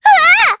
Estelle-Vox_Damage_jp_4.wav